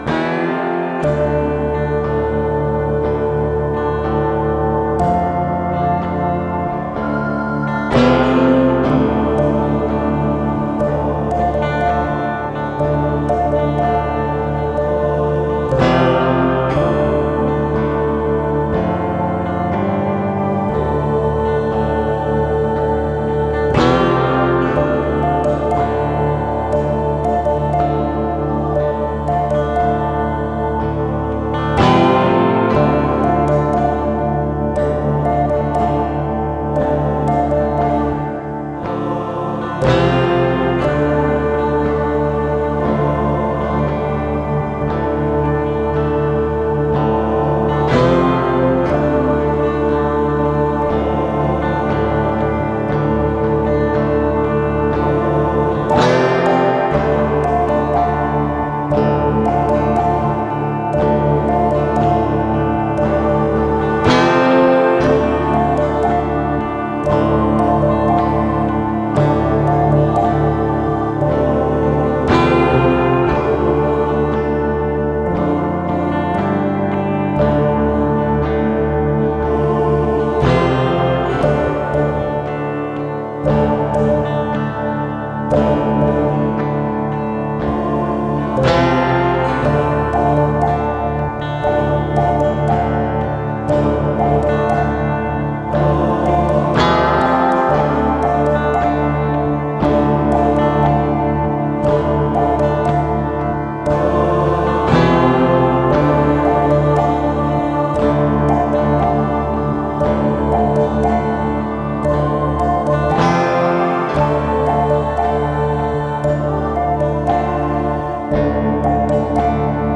Western Gitarre mit Orgel, episch .